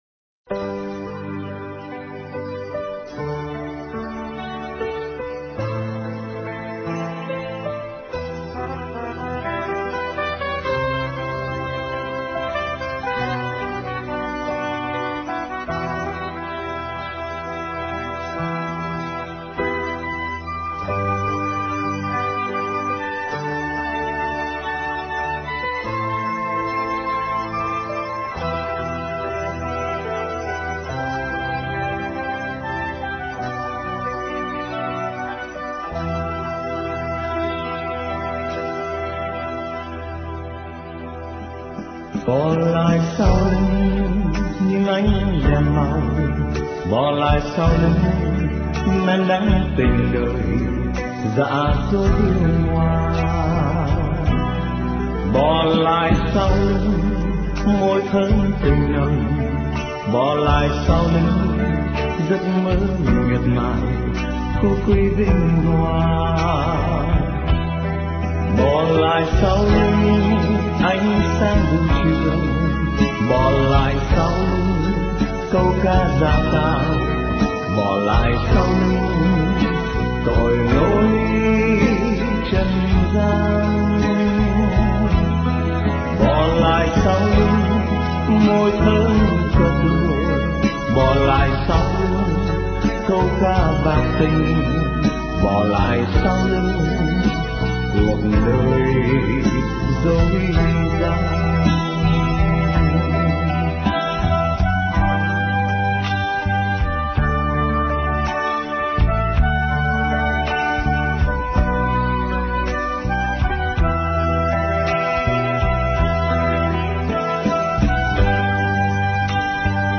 * Thể loại: Đức Mẹ